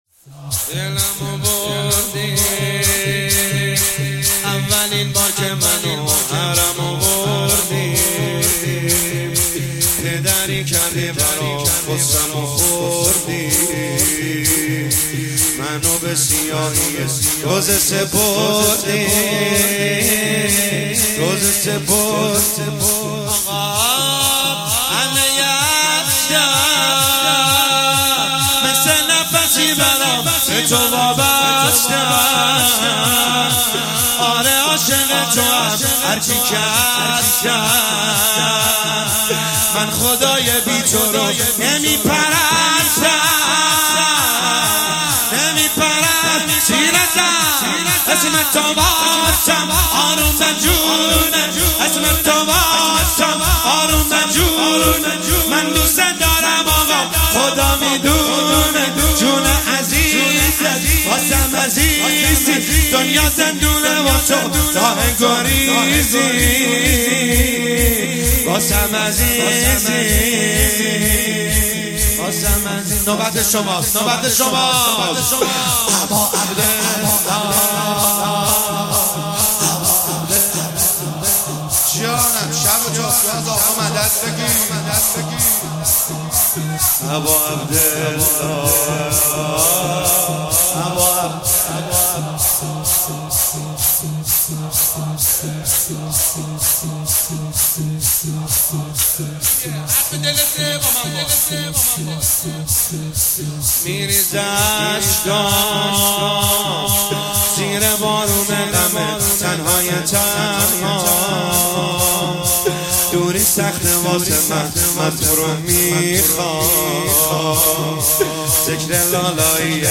شب تاسوعا محرم1401 - شور